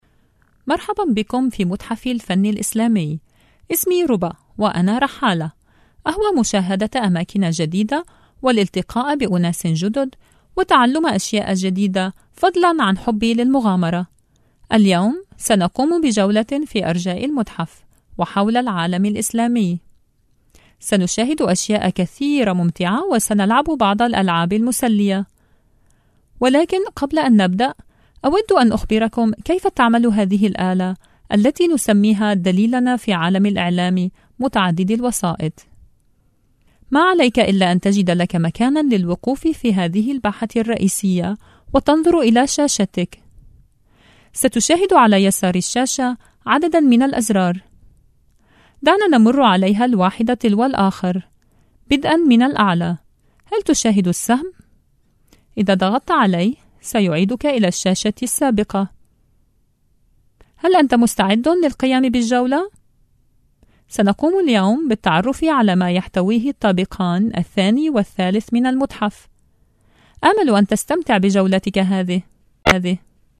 Radio producer, warm, experienced, Arabic and English.
Islamic Art Museum Narration Arabic